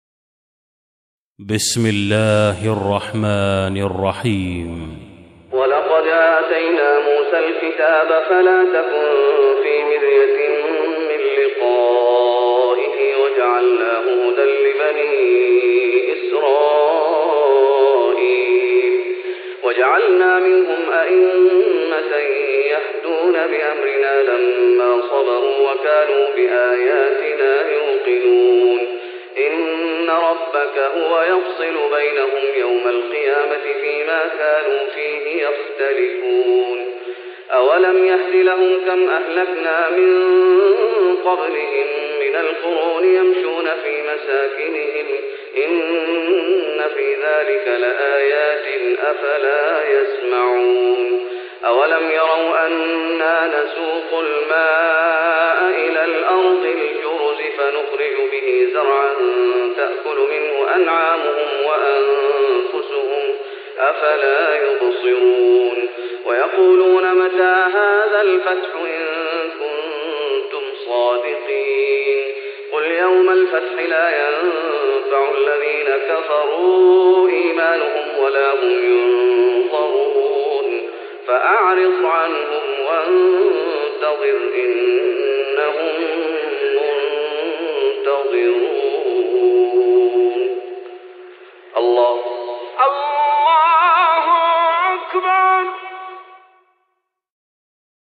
تراويح رمضان 1415هـ من سورة السجدة (23-30) Taraweeh Ramadan 1415H from Surah As-Sajda > تراويح الشيخ محمد أيوب بالنبوي 1415 🕌 > التراويح - تلاوات الحرمين